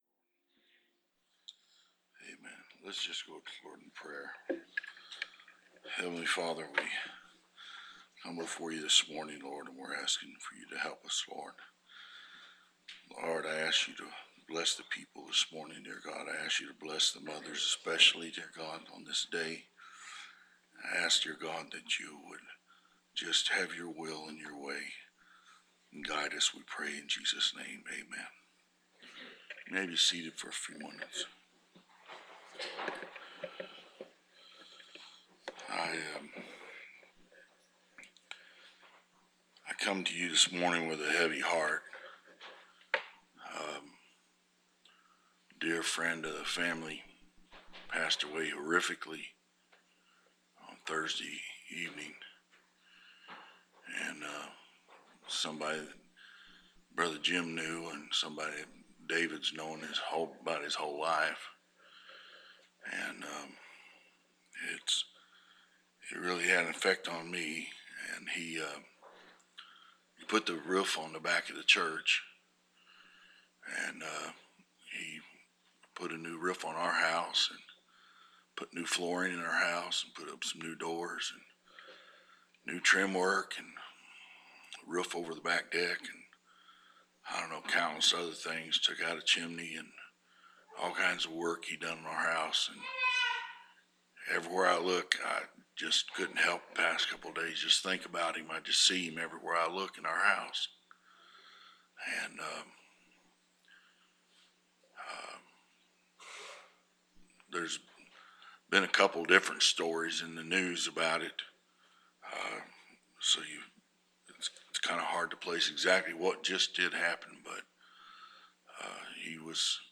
Preached May 9th, 2021